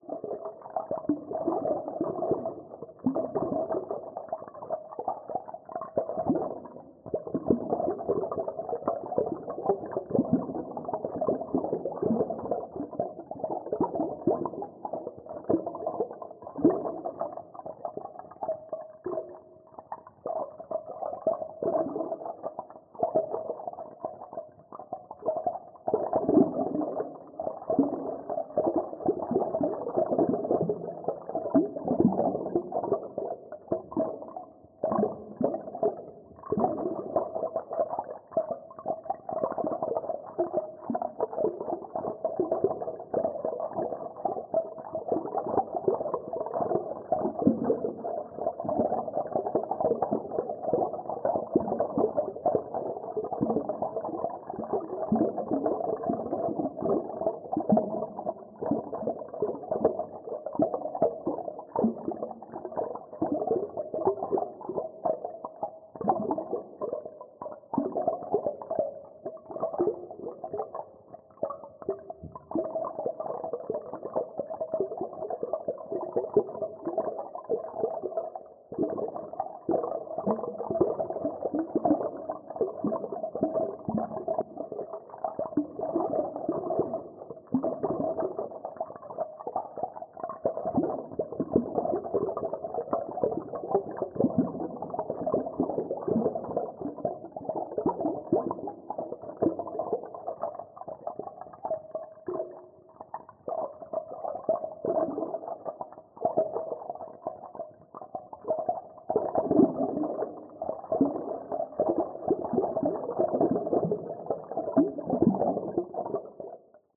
Dive Deep - Small Bubbles 01.wav